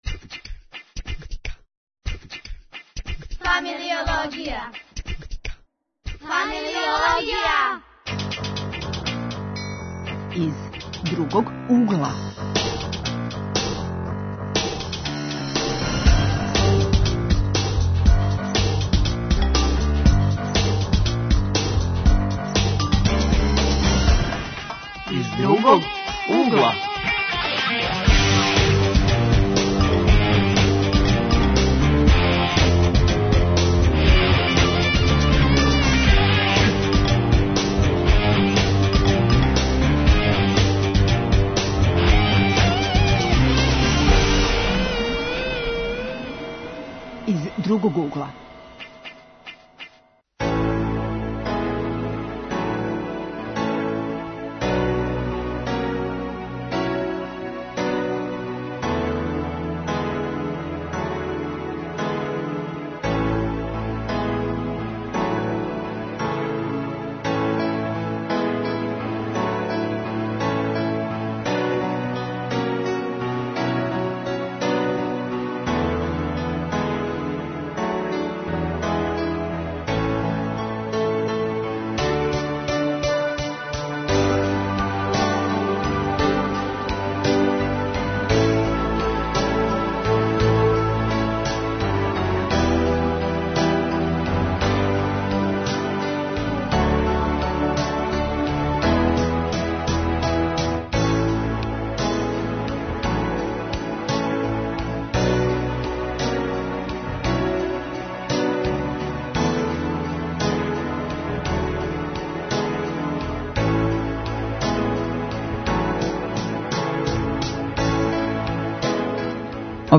Гости су нам лади који студирају у свету, а празнике проводе у родном Београду.